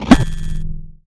sci-fi_spark_electric_device_active_02.wav